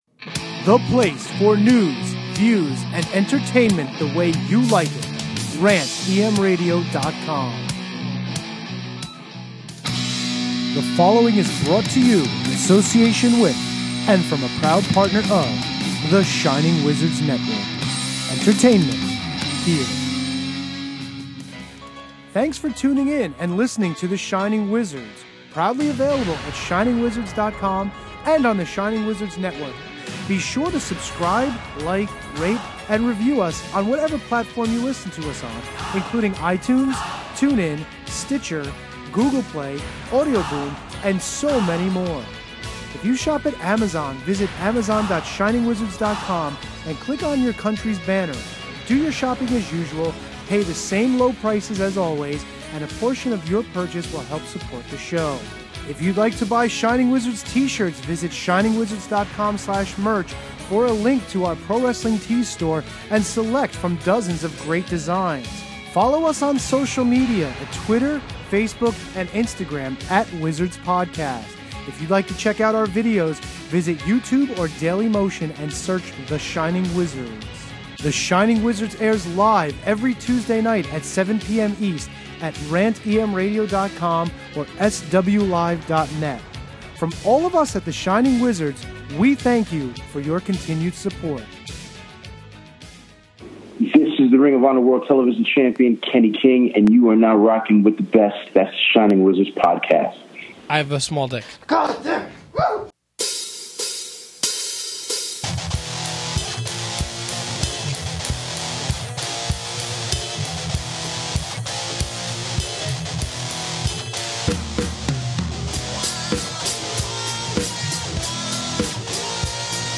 Disclaimer: the reason this episode was delayed for a few days is because the original recording became corrupted.
While it isn’t the greatest quality, we hope that you can still enjoy what is an awesome episode.